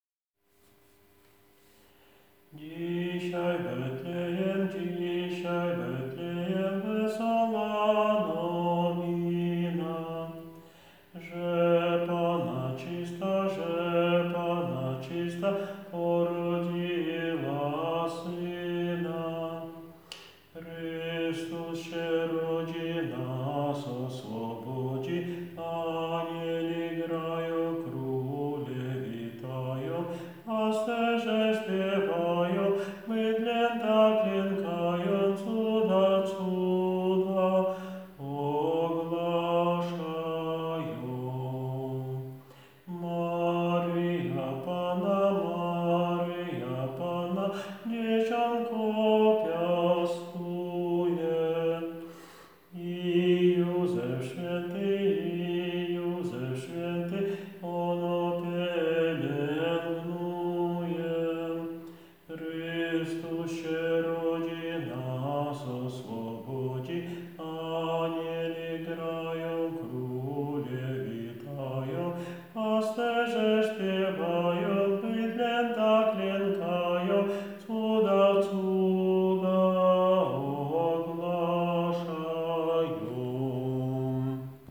Dzisiaj w Betlejem Sopran 2 - nagranie utworu z głosem nauczyciela ze słowami a capella (bez pomocy instrumentu)